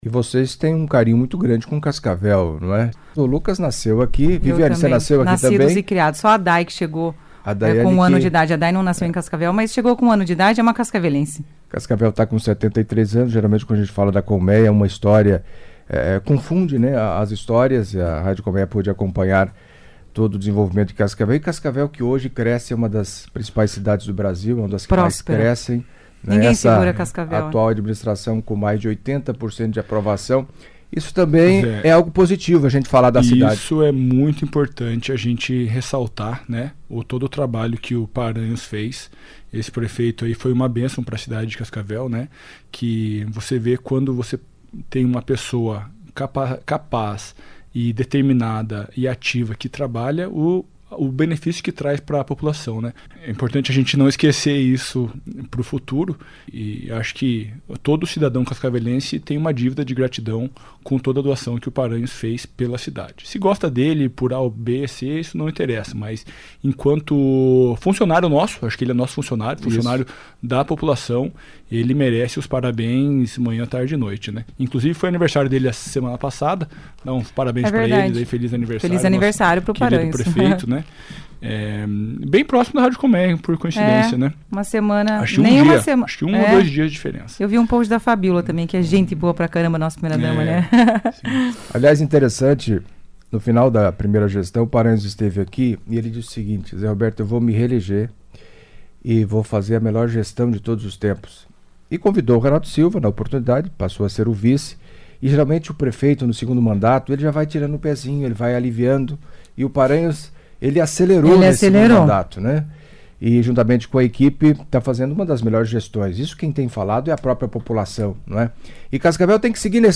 Em entrevista à CBN Cascavel nesta terça-feira (28)